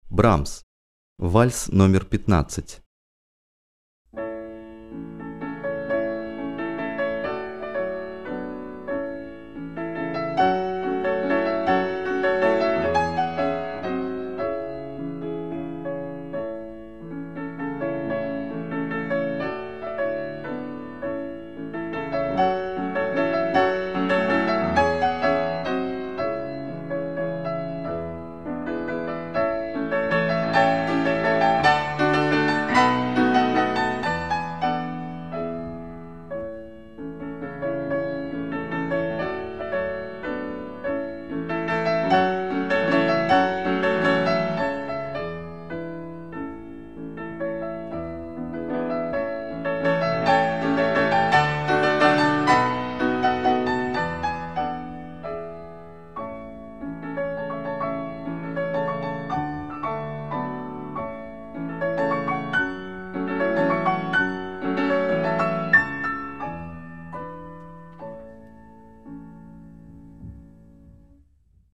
Категория: Класика